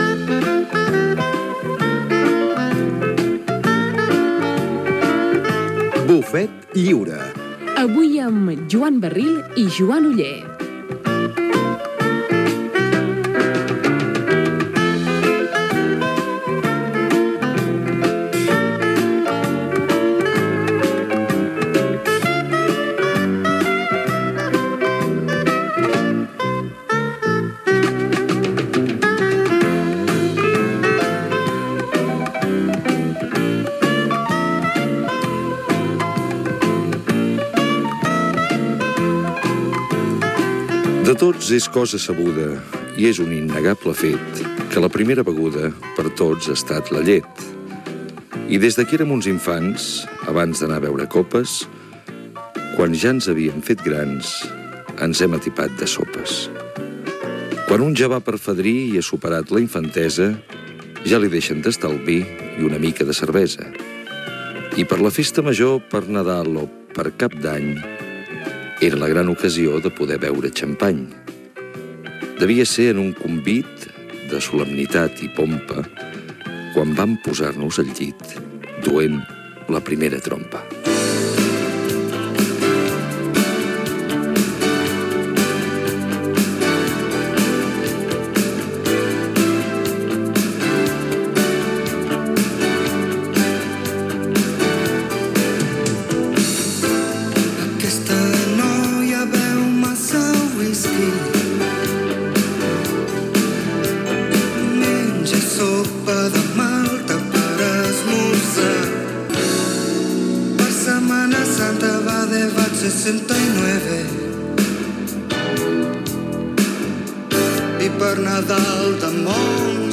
Careta del programa, espai dedicat a les begudes, amb músiques i escrits